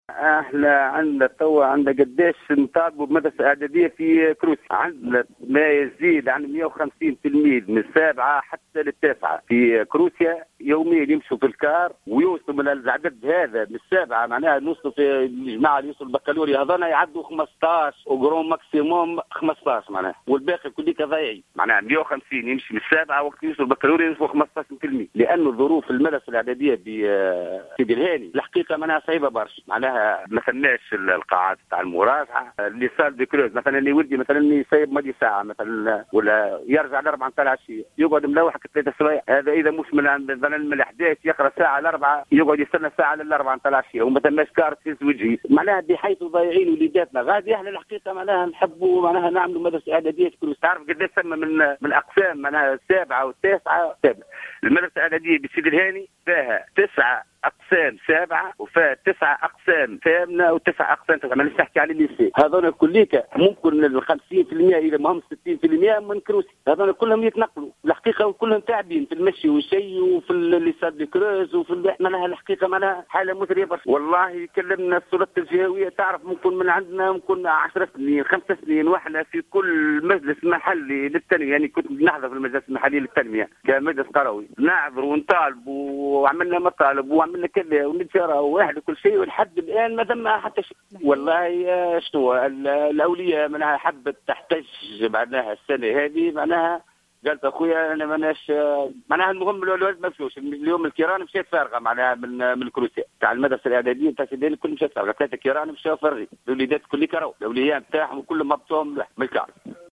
أحد الأولياء يتحدث للجوهرة أف أم